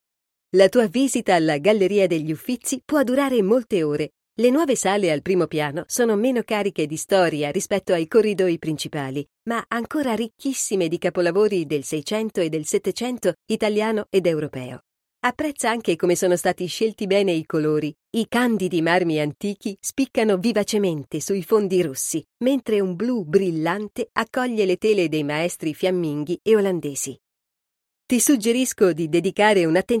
• Audioguida Multilingua